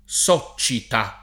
soccida [S0©©ida] s. f. (giur.) — antiq. soccita [